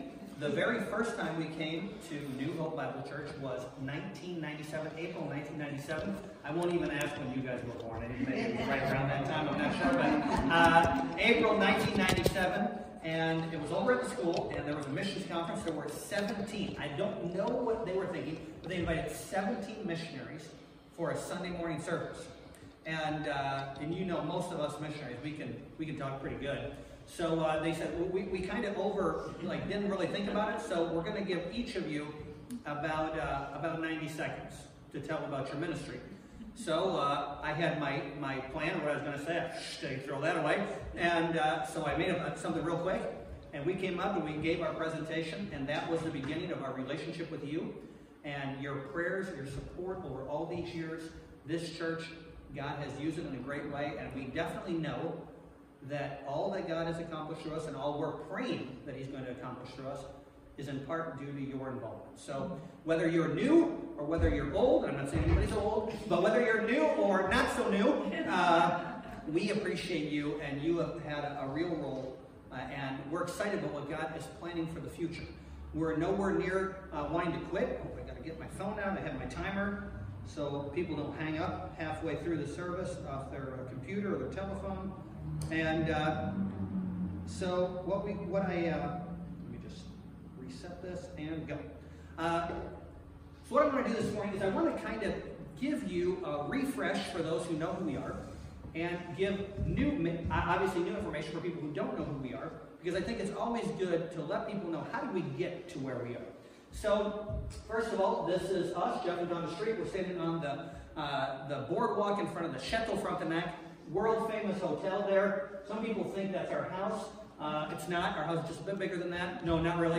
Missionary Sermons